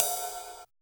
626 RIDE.wav